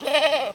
goat_call_03.wav